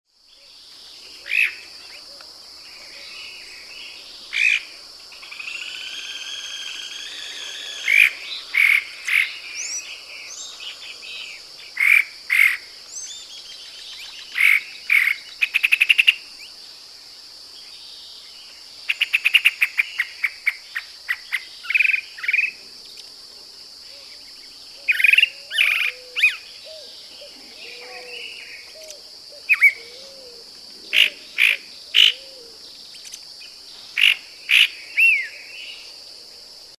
Sabiá-do-campo
Mimus saturninus
Gosta de pousar por longos períodos de tempo em galhos secos ou postes para entoar seu belo canto, no qual incorpora vozes de outras espécies de aves.
Nome em Inglês: Chalk-browed Mockingbird
Aprecie o canto do
sabiadocampo.mp3